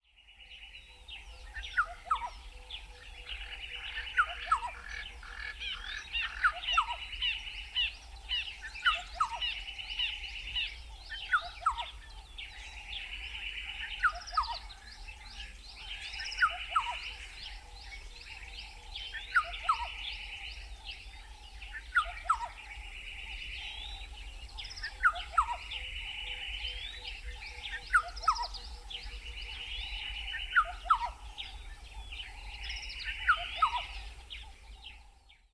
“More often heard than seen” – the Eastern Black-headed Oriole
Black Headed Oriole
The oriole is the soundtrack to peaceful mornings on safari.
It is the sound of a peaceful morning in camp, where the stresses of everyday life are a million miles away.
Here, you can listen to the sound of the black-headed oriole which is sure to take you back to that blissful safari in Africa.